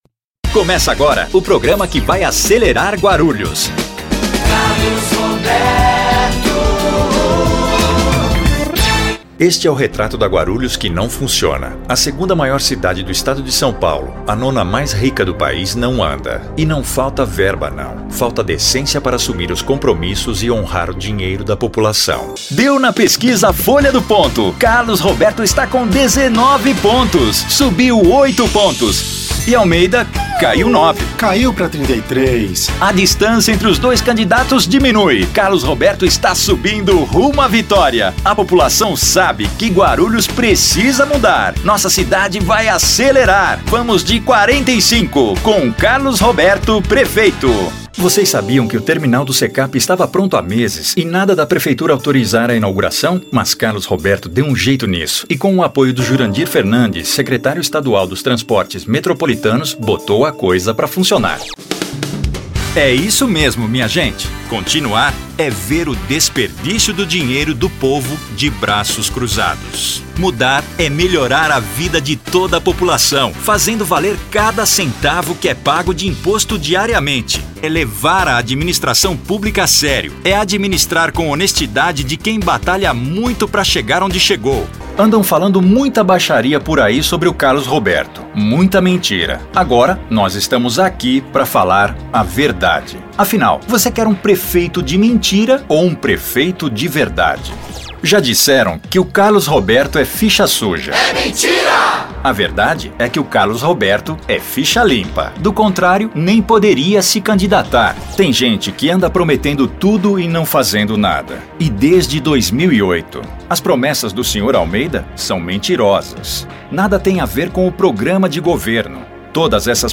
Locução para Campanha Política da Prefeitura de Guarulhos 2012